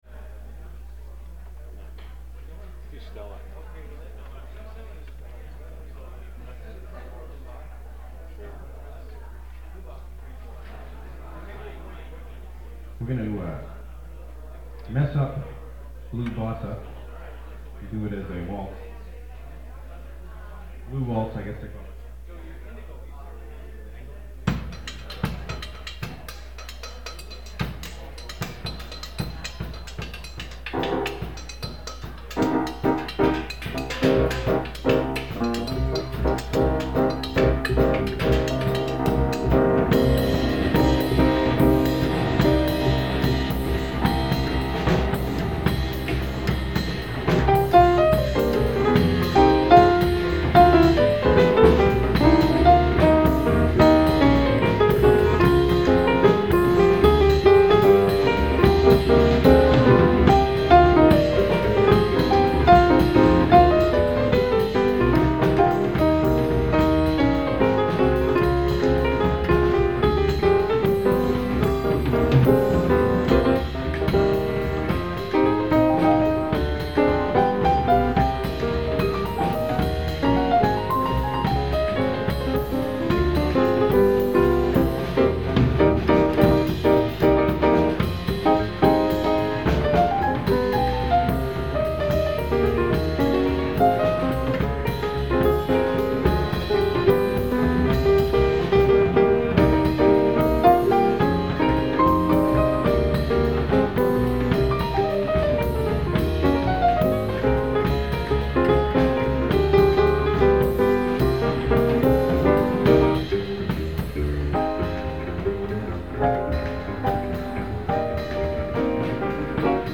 piano
bass
drums   Button Factory